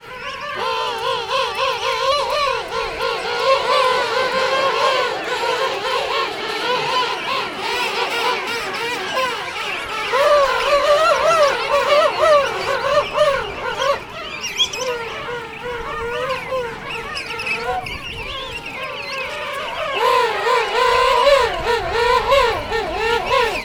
King penguins at Sandy Bay on Macquarie Island
The chicks’ high-pitched calls can be heard amongst the adults.
king-penguins-macquarie-island.mp3